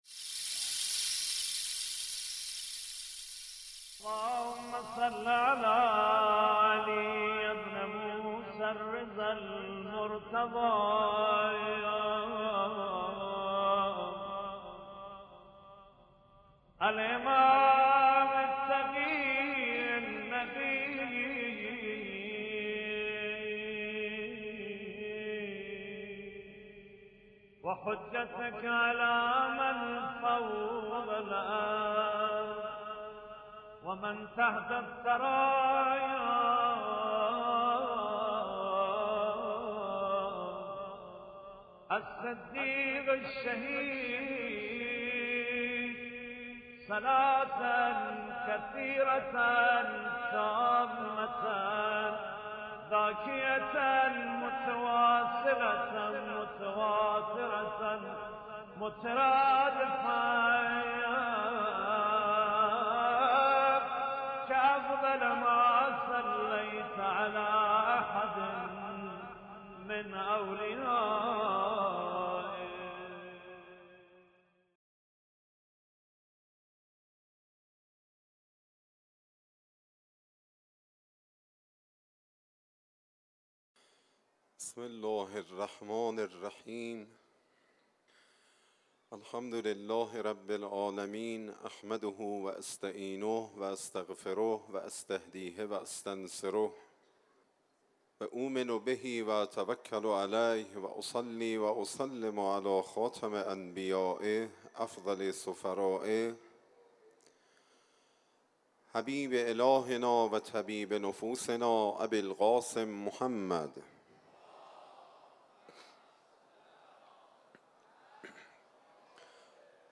شب اول - حرم امام رضا علیه السلام - تعاریف عقل